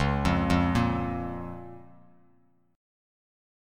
C#6 Chord
Listen to C#6 strummed